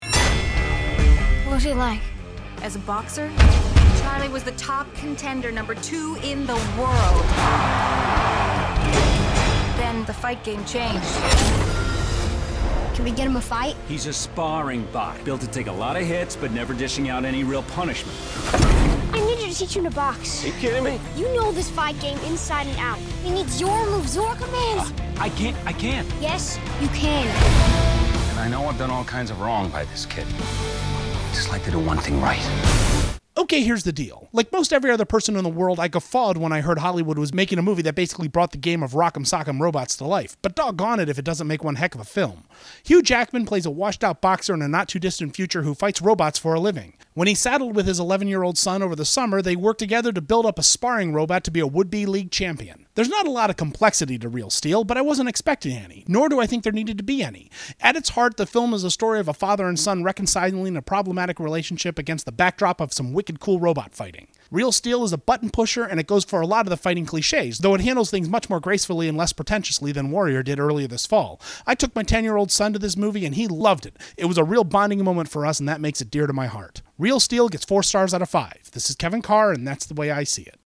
Movie Review: ‘Real Steel’